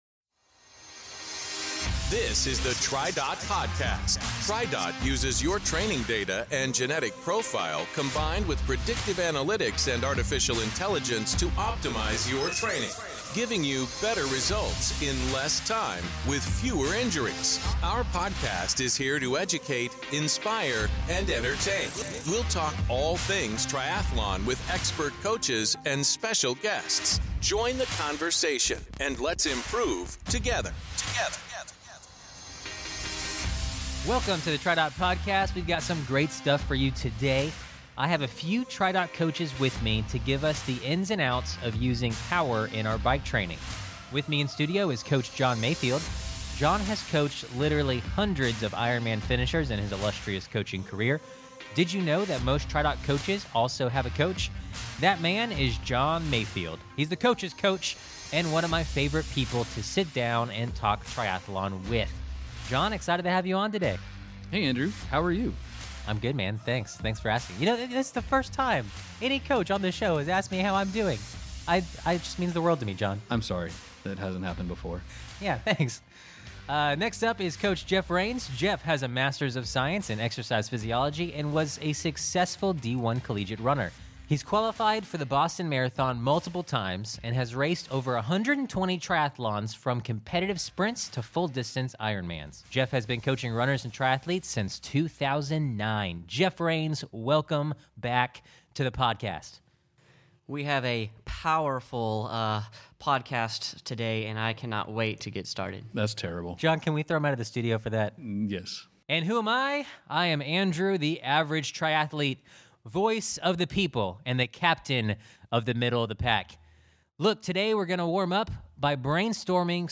We'll talk all things 0:23.6 triathlon with expert coaches and special guests.